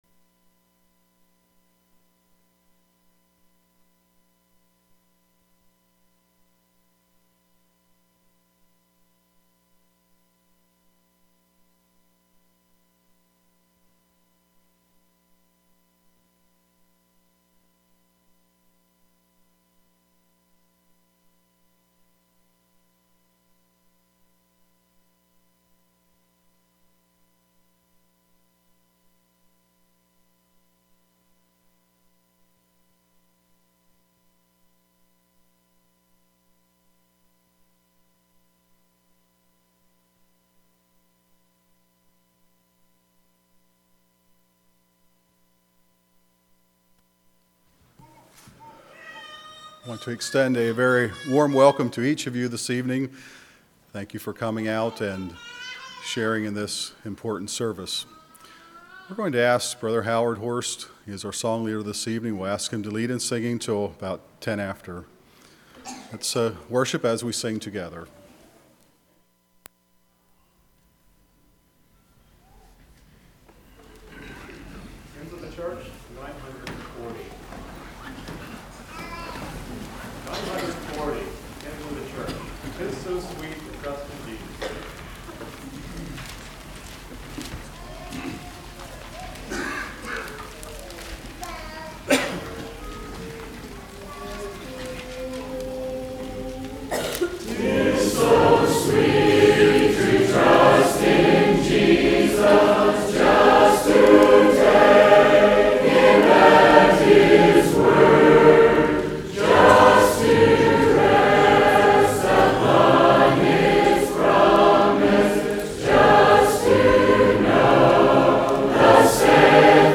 Minister Ordination
Minister Ordination at Swatara.